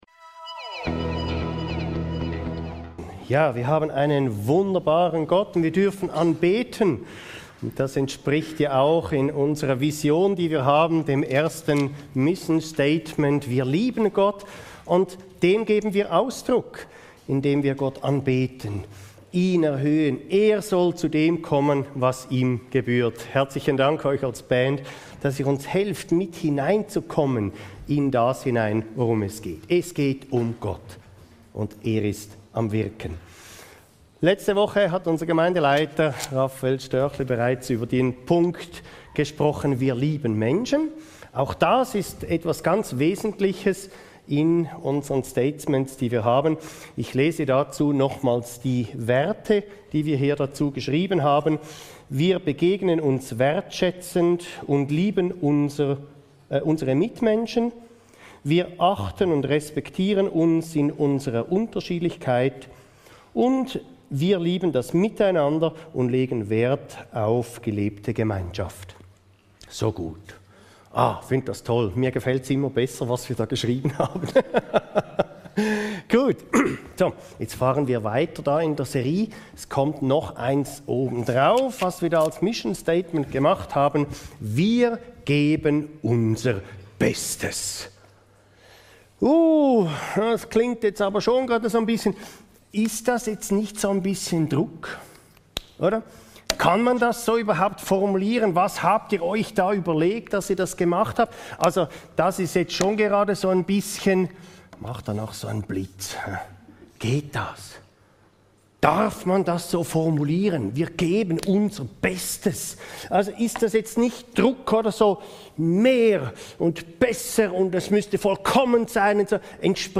Vision – Wir geben unser Bestes ~ Your Weekly Bible Study (Predigten) Podcast